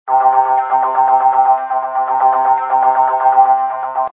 popTwo_bass00.mp3